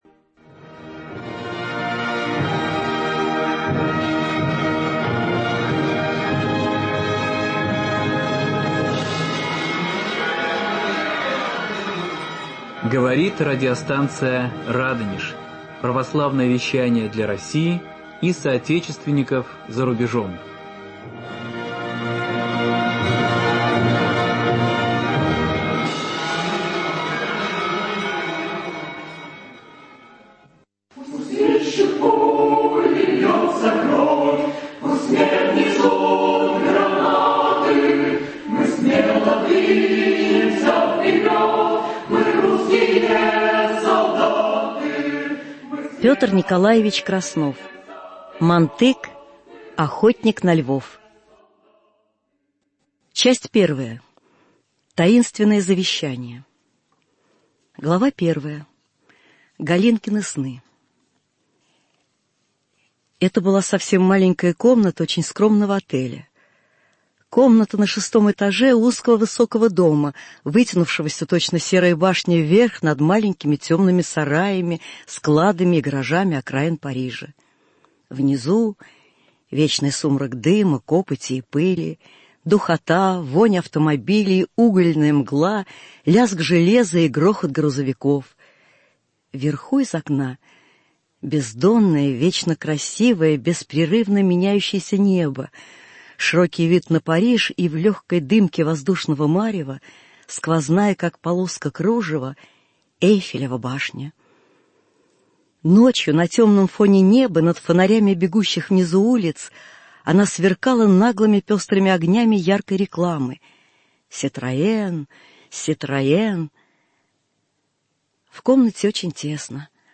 аудиокниги